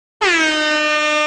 AA-ClubHorn